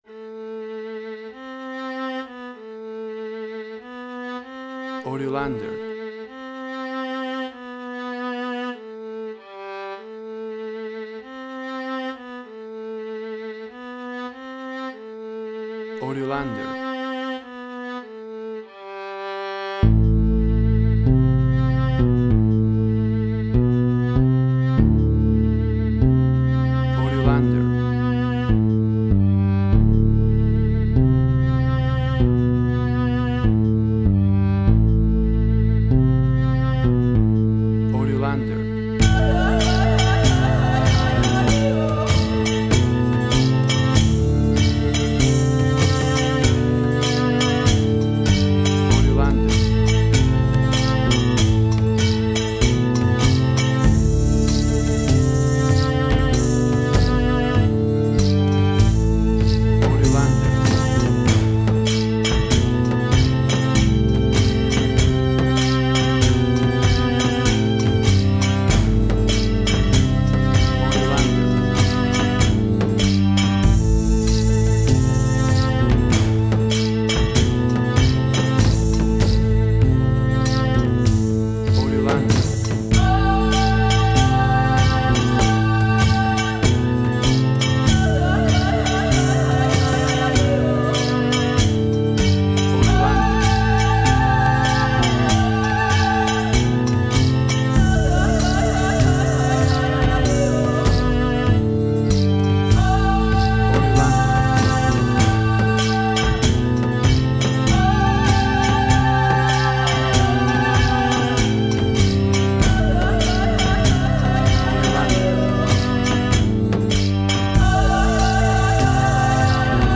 emotional music
Tempo (BPM): 49